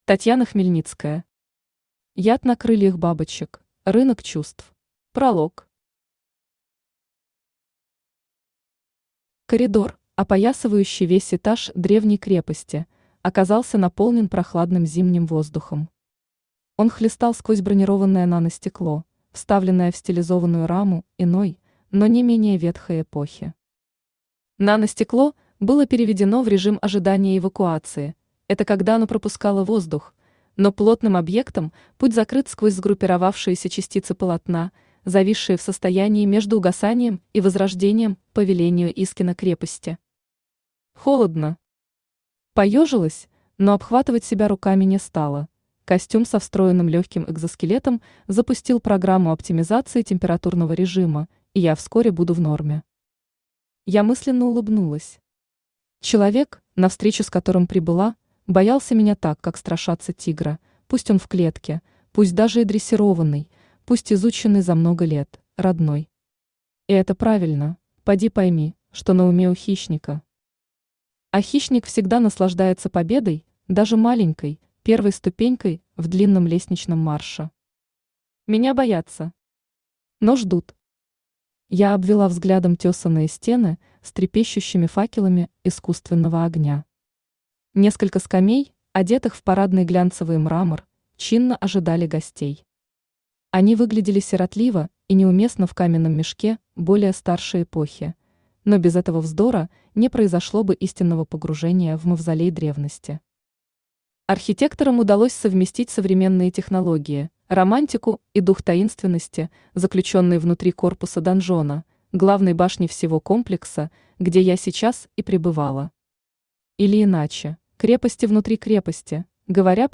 Аудиокнига Яд на крыльях бабочек: рынок чувств | Библиотека аудиокниг
Aудиокнига Яд на крыльях бабочек: рынок чувств Автор Татьяна Хмельницкая Читает аудиокнигу Авточтец ЛитРес.